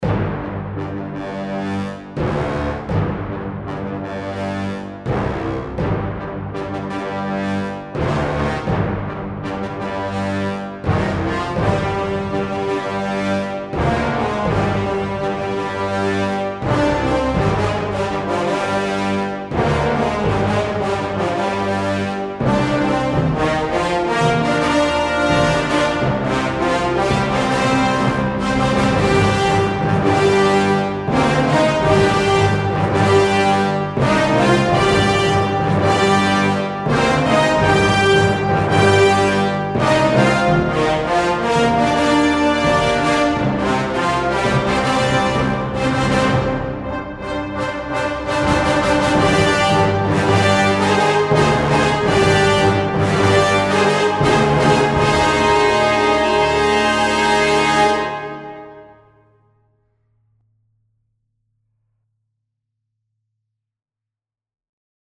LW_BRASS_TEST.mp3